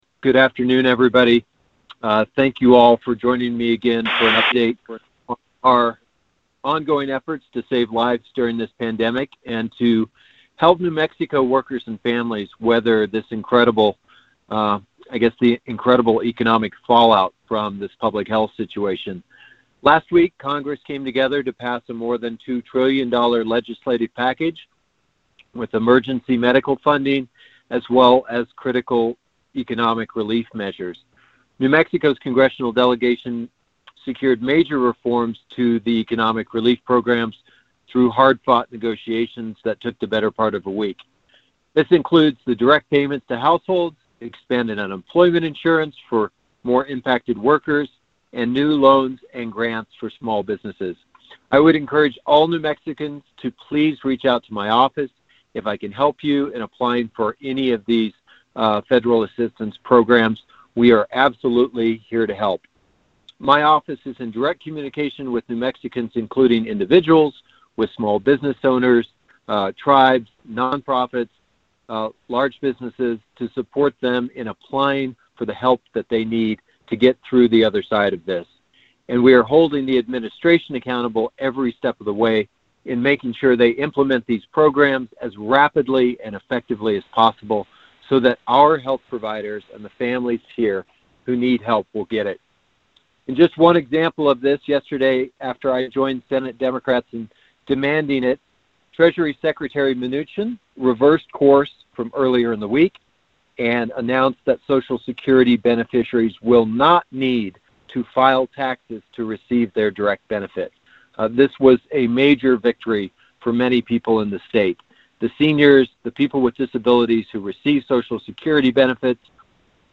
WASHINGTON – U.S. Senator Martin Heinrich (D-N.M.) today held a teleconference with New Mexico-based reporters to answer questions and discuss the latest on efforts to assist New Mexicans impacted by the COVID-19 pandemic.